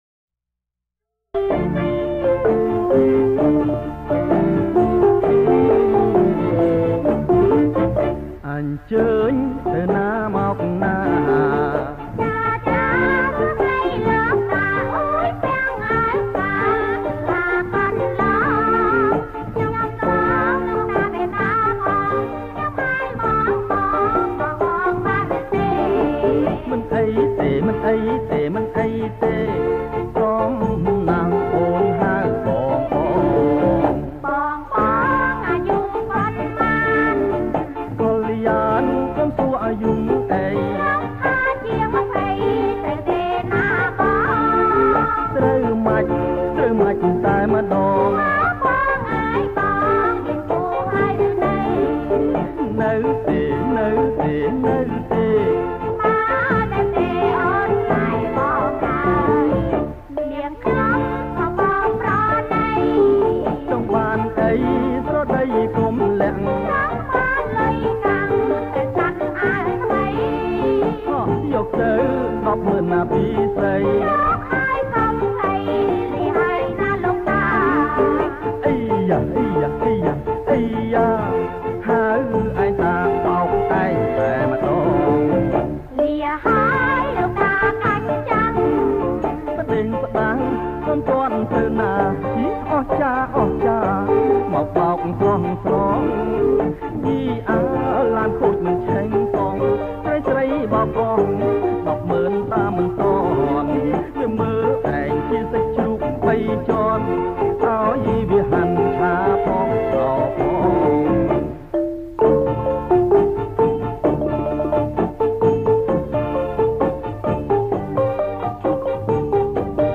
ប្រគំជាចង្វាក់ រាំវង់